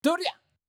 戦闘 掛け声 気合い ボイス 声素材 – Battle Cries Voice